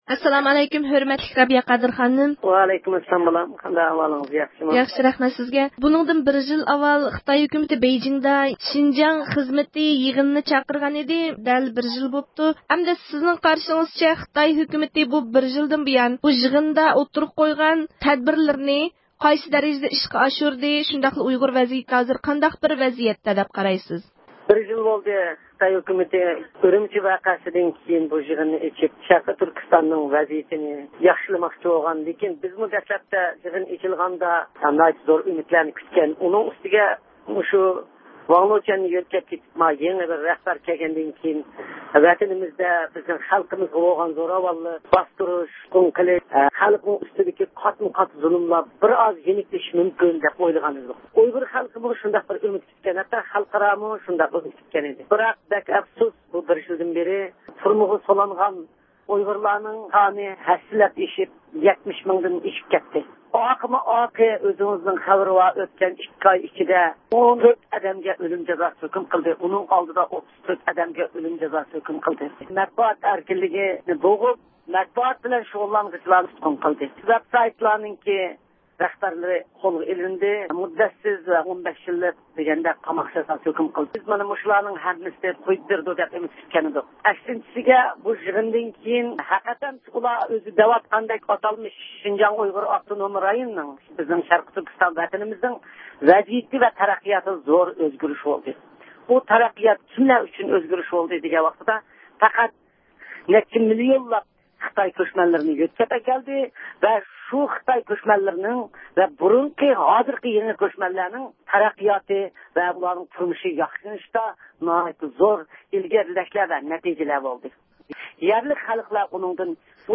يۇقىرىدىكى ئاۋاز ئۇلىنىشى ئارقىلىق ئۇيغۇر مىللىي ھەرىكىتى رەھبىرى رابىيە قادىر خانىم بىلەن مۇخبىرىمىزنىڭ سۆھبىتىنى ئاڭلىغايسىلەر.